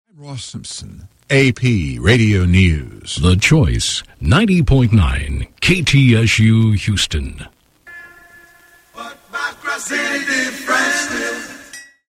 KTSU Top of the Hour Audio: